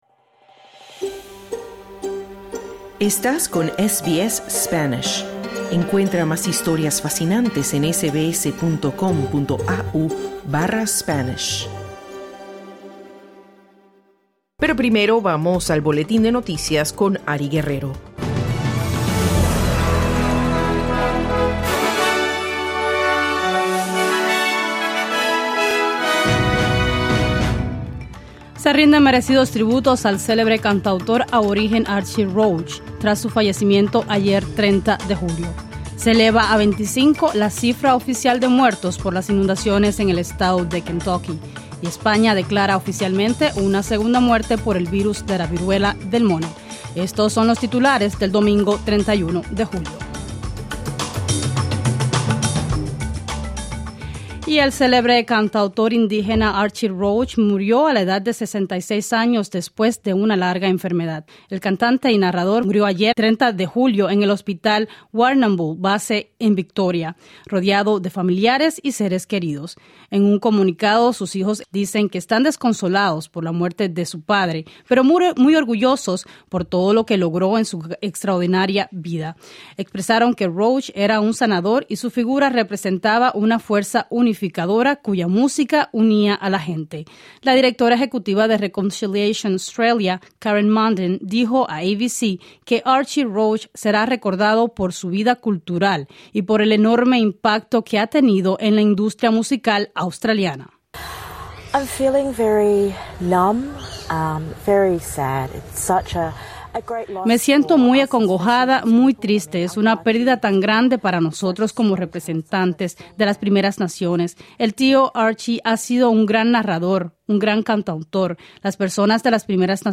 Noticias SBS Spanish | 31 julio 2022